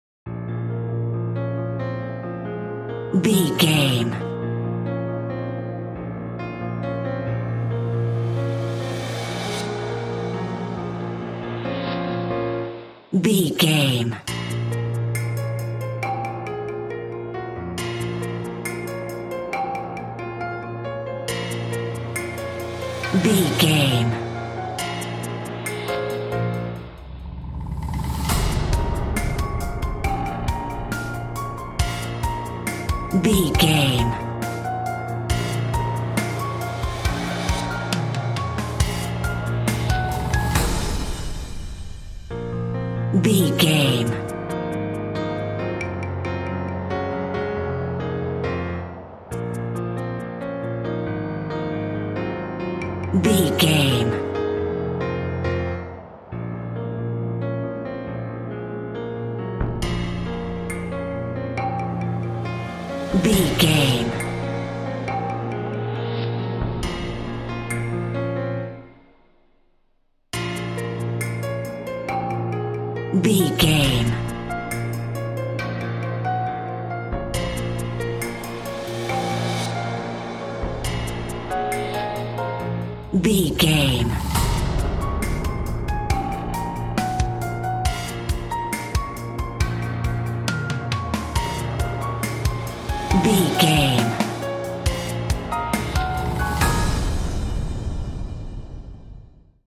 Aeolian/Minor
B♭
tense
eerie
haunting
medium tempo
drum machine